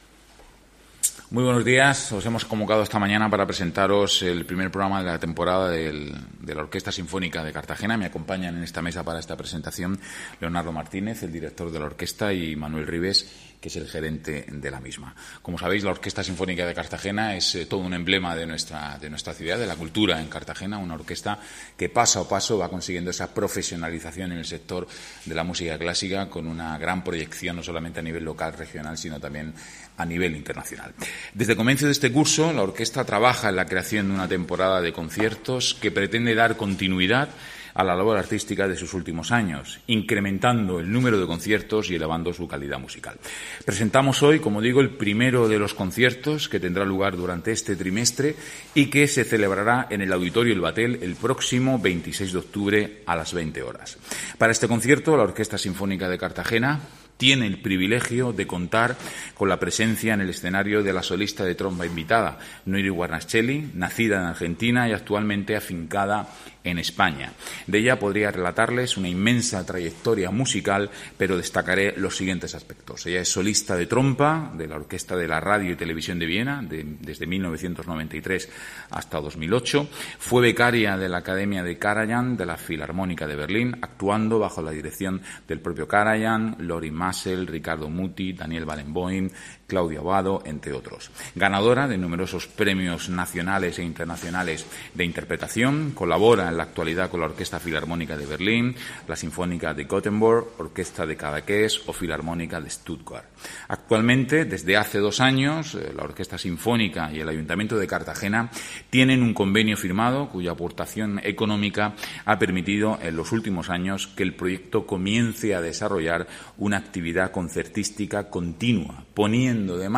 Audio: Presentaci�n del primer concierto del Ciclo de la Orquesta Sinf�nica de Cartagena (MP3 - 4,15 MB)